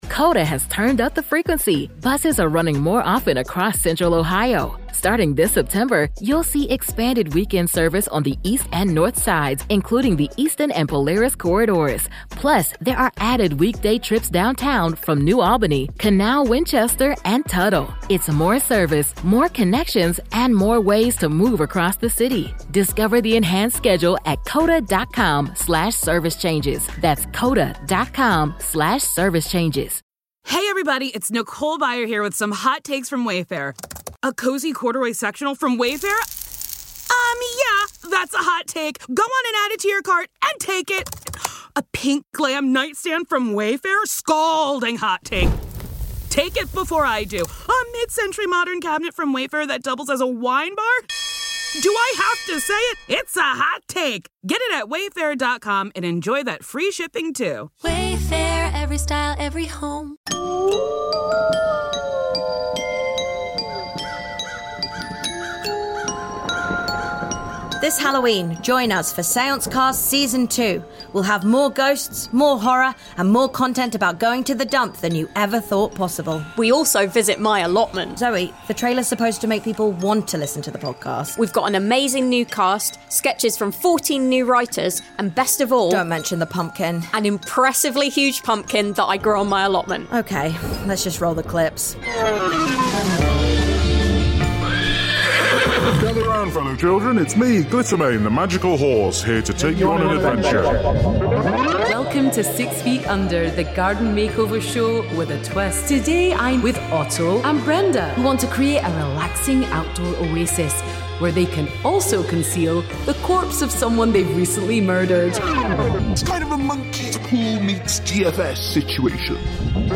This Halloween the British Podcast Award winning comedy-horror sketch show Seancecast is back for a second series.